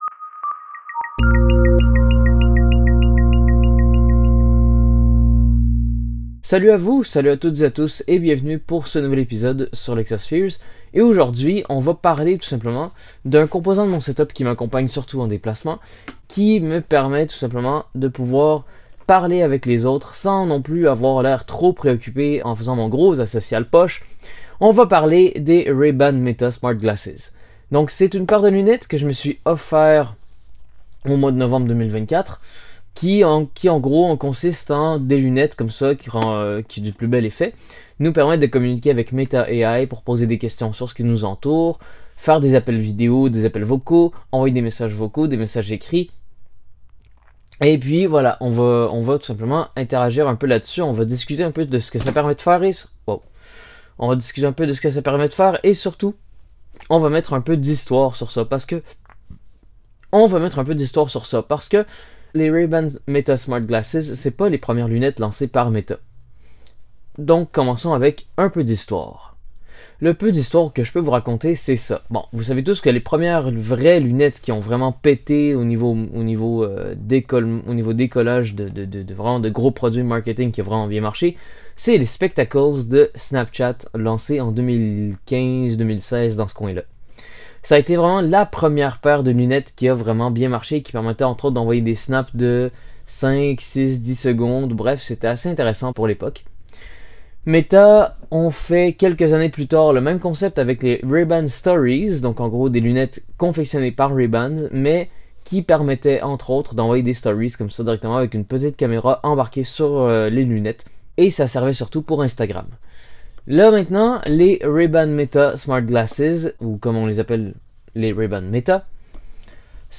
Salut à vous, Aujourd’hui, histoire de flex lol, je vous propose un petit podcast enregistrer en binaural. Il porte sur les lunettes Meta Rayban. Je vous montre quelques fonctionnalités qui selon moi, sont juste parfaites.
Il est recommander de porter un casque d’écoute ou une bonne paire d’écouteurs pour apprécier ce contenu.